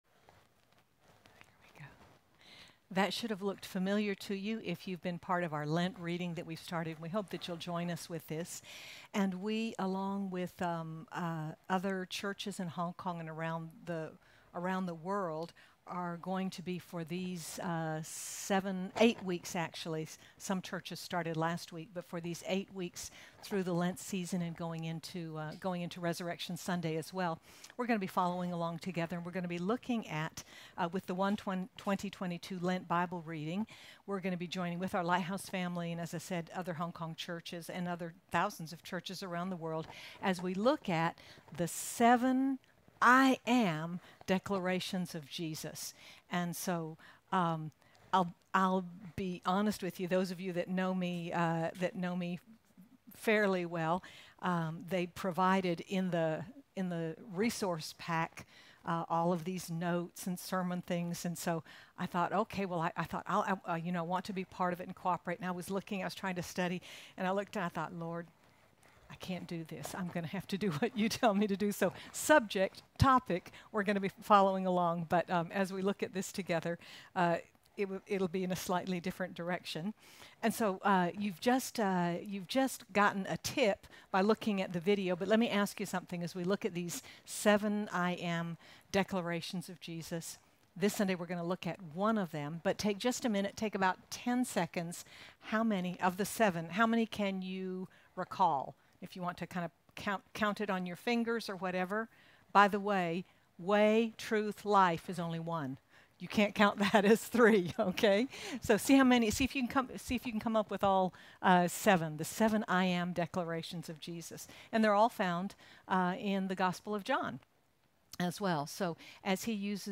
This first message highlights Jesus and our essential need for the Bread of Life. Sermon by